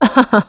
Valance-Vox_Happy2.wav